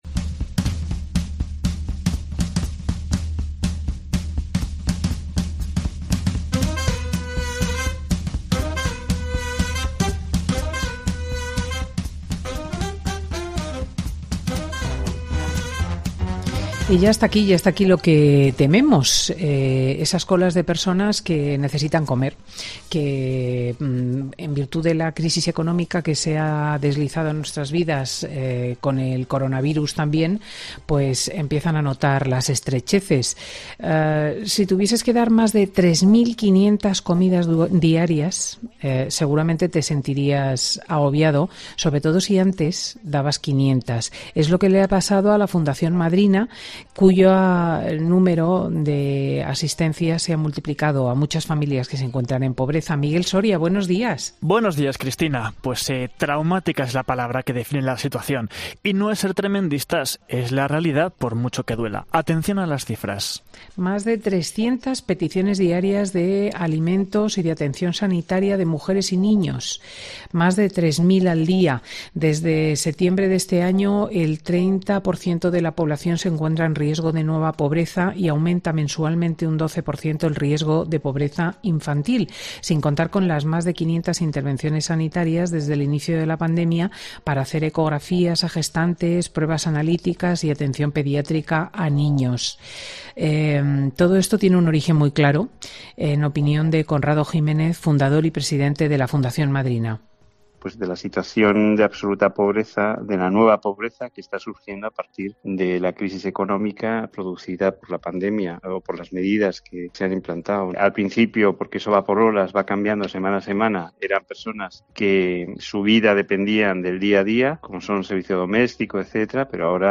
El juez de menores de Granada habla en Fin de Semana